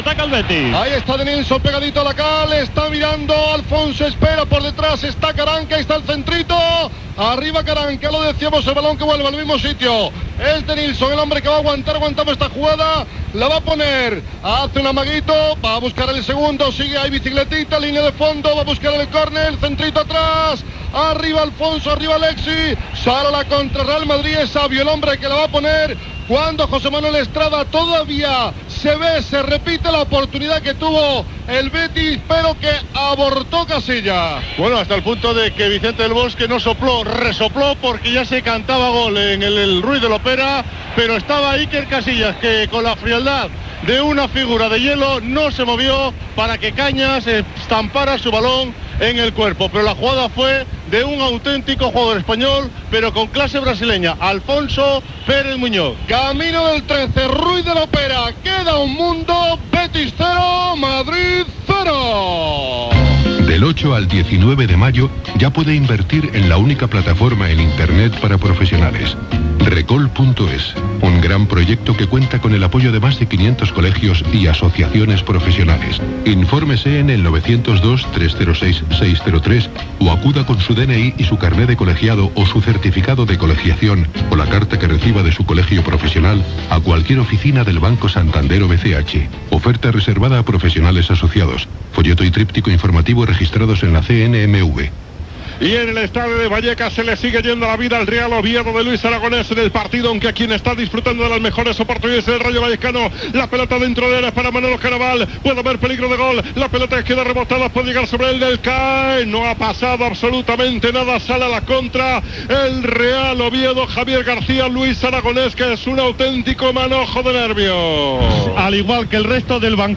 cd4661ac918afb518f29d98cd9f7ae732cc4d2ca.mp3 Títol Cadena COPE Emissora Ràdio Popular de Barcelona (COPE Barcelona) Cadena COPE Titularitat Privada estatal Nom programa Tiempo de juego Descripció Connexió amb els partit Betis -Real Madrid i Rayo Vallecano -Oviedo.
Resum de com van tots els partits relacionats amb l'ascens i el descens de lliga Gènere radiofònic Esportiu Anunciant Banco Santander, El Corte Inglés, Banco Bilbao Vizcaya, Finisterre, Ducados, Halcón Viajes, Puritos Reig.